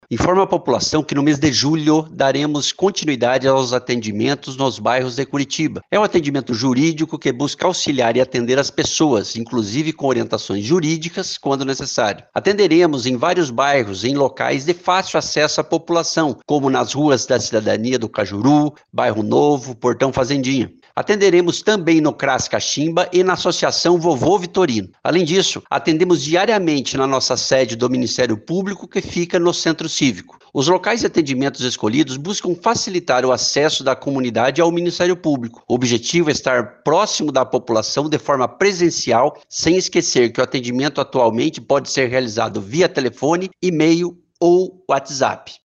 Os atendimentos descentralizados têm como objetivo, segundo a NACC, ampliar o acesso da população ao sistema de justiça, tornando os serviços do MPPR mais acessíveis para as comunidades distantes das regiões centrais. Sobre o procedimento, o promotor de justiça das comunidades do MPPR, Régis Sartori explica de forma mais detalhada.